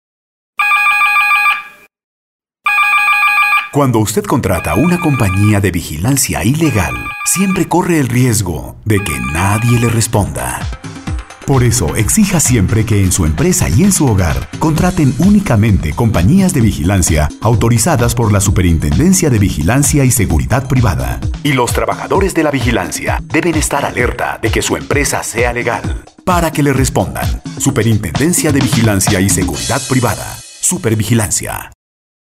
Cuña Radial -8.3 | Supervigilancia